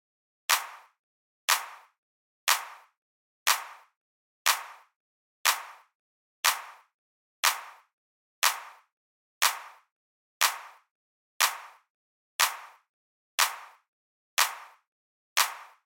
描述：我演奏的两段旋律的混合。其中一个是在小鼓上用刷子刷的。在我的电子鼓上演奏和录音。
标签： 121 bpm Electro Loops Drum Loops 2.67 MB wav Key : Unknown
声道立体声